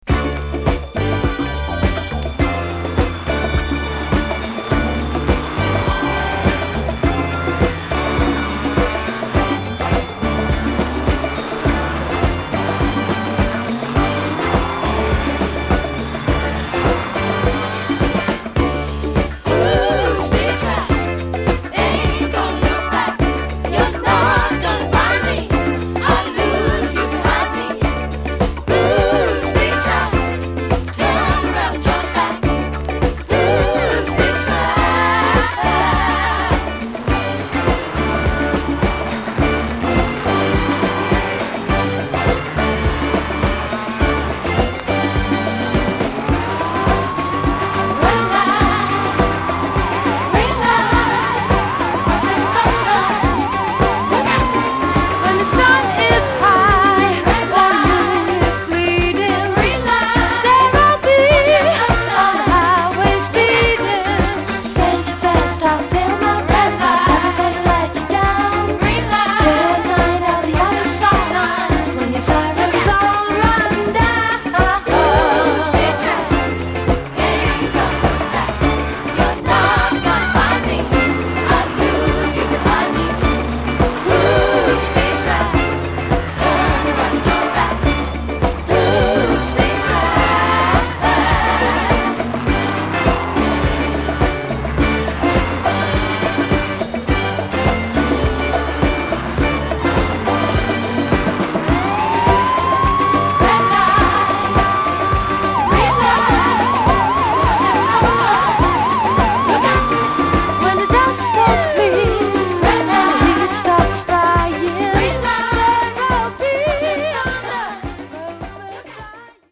Funk / Rare Groove holland
他にもエンジン音やサイレン音が入っていたりして